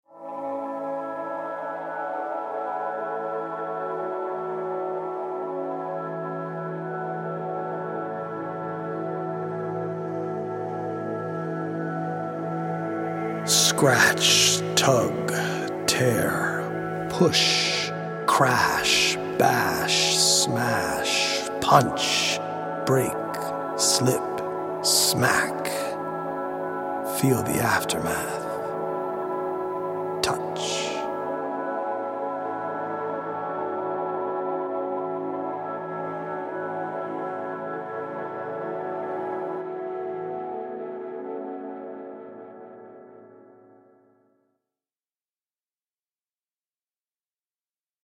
100 original poems written/performed
healing Solfeggio frequency music
EDM